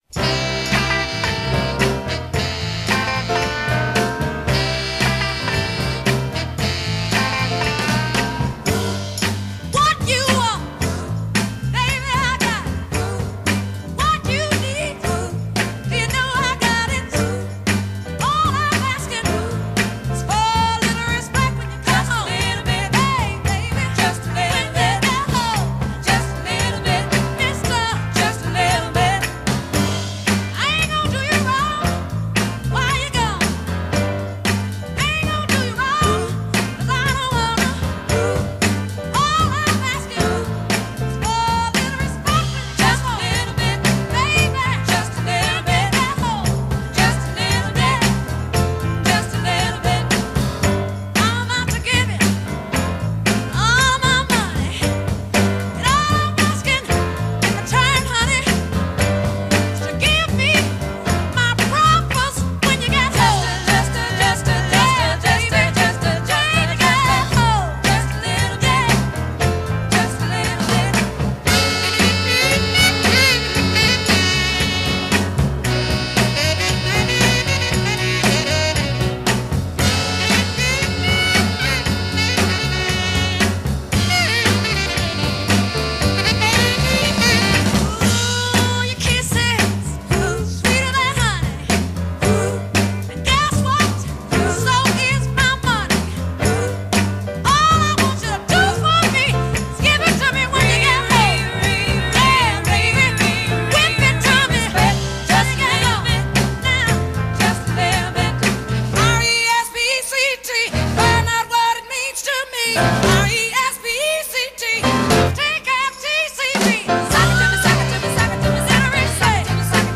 durante la siguiente hora gozaremos con la reina del Soul en bittorrent, UPVRadio y esta misma web.